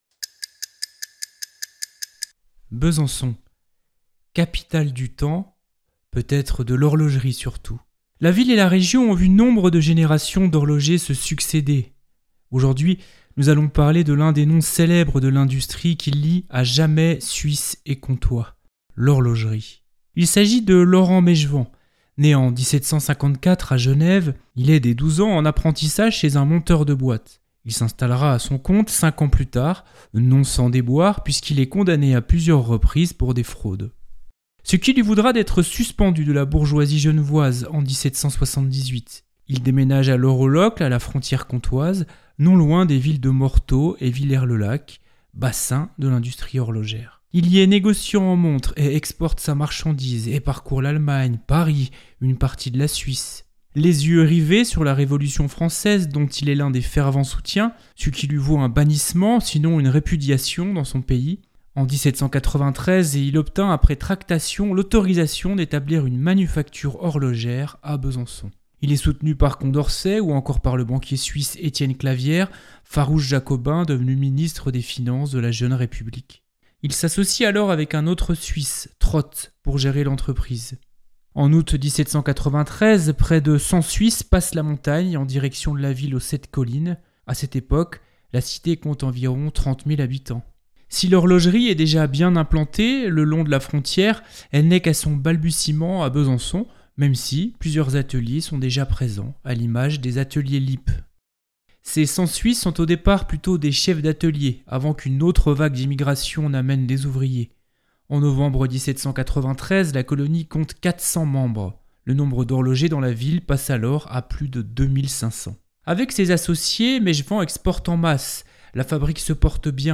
Extrait film : Désordres de  Cyril Schäublin
Musique : MANO NEGRA-Out of time man